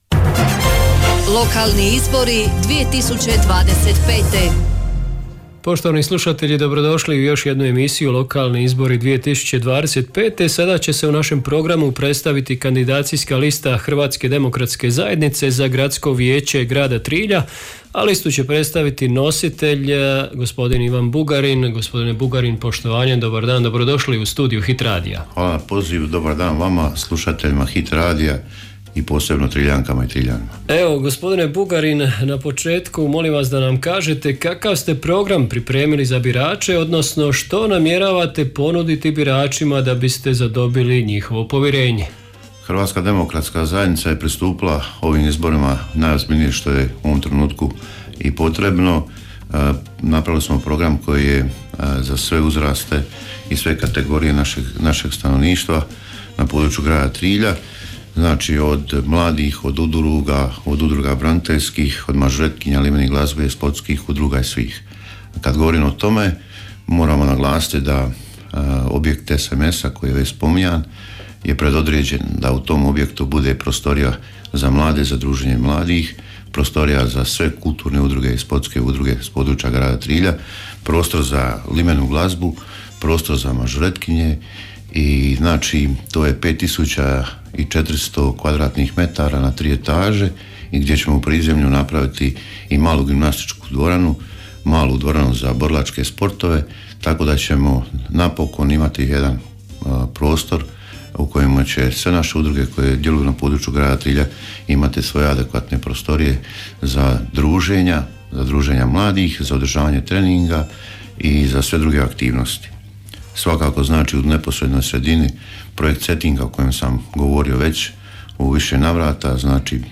Hit radio prati izbore u 7 jedinica lokalne samouprave (Grad Vrlika, Općina Dicmo, Općina Hrvace, Općina Dugopolje, Općina Otok, Grad Trilj, Grad Sinj). Sve kandidacijske liste i svi kandidati za načelnike odnosno gradonačelnike tijekom službene izborne kampanje imaju pravo na besplatnu emisiju u trajanju do 10 minuta u studiju Hit radija.